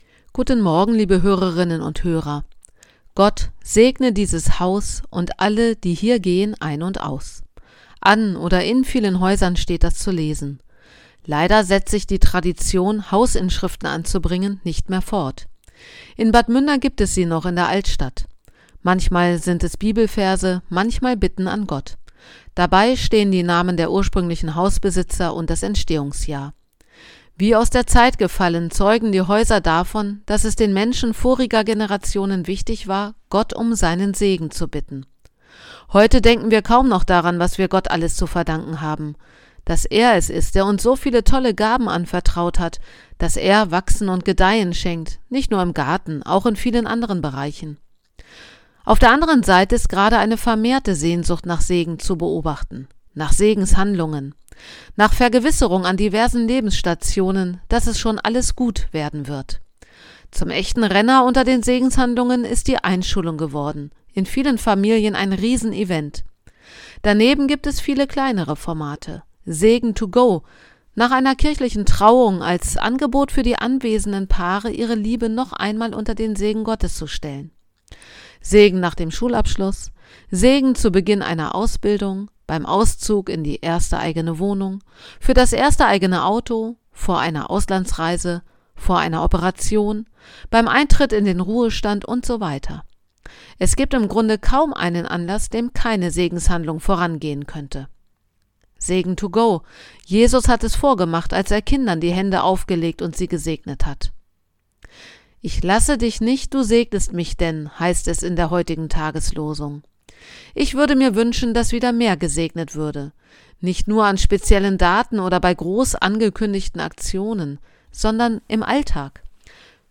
Radioandacht vom 17. Oktober